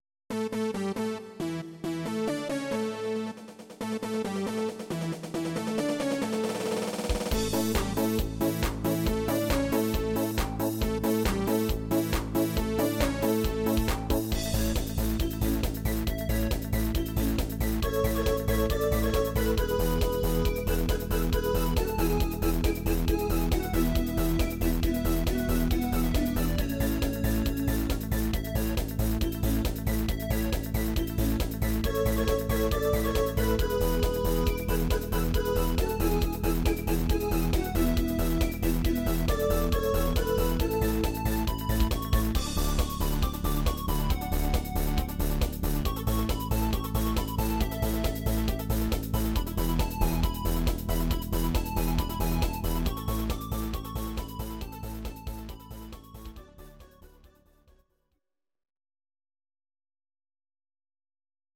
Audio Recordings based on Midi-files
Pop, Dutch, 1990s